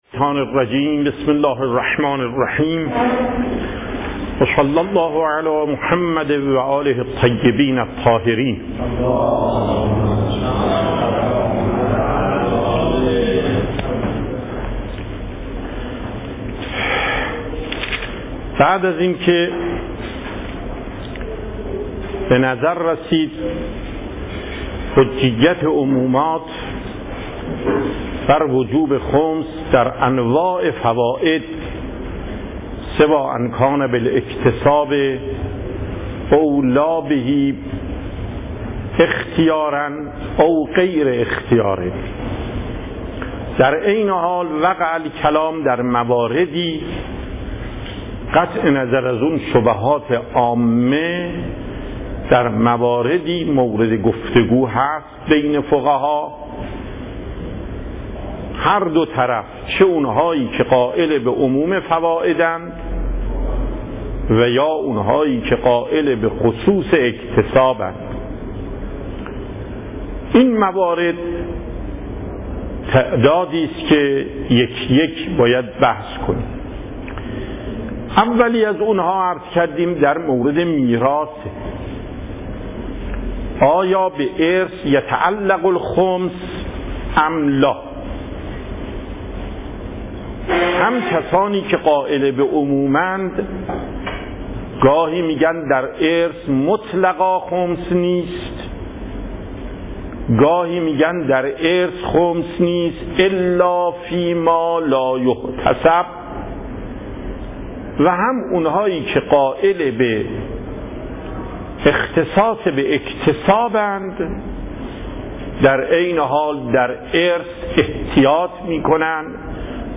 صوت و تقریر درس پخش صوت درس: متن تقریر درس: ↓↓↓ تقریری ثبت نشده است.
درس فقه آیت الله محقق داماد